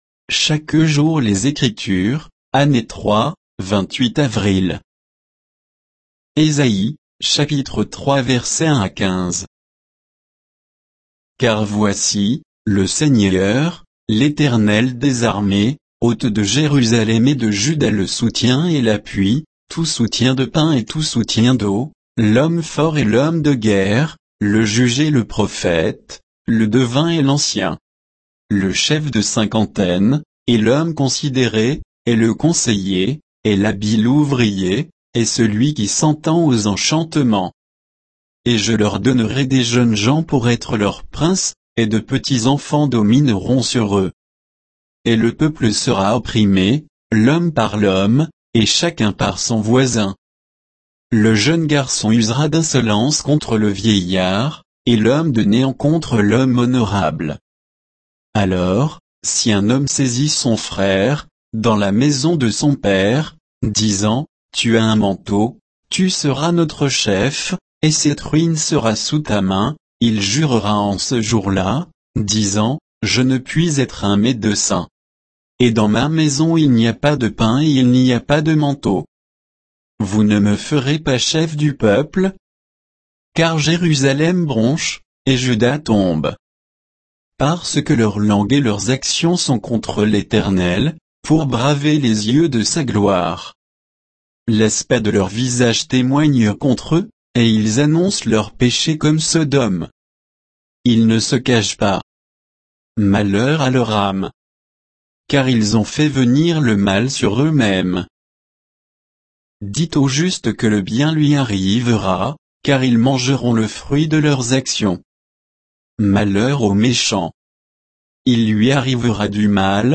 Méditation quoditienne de Chaque jour les Écritures sur Ésaïe 3, 1 à 15,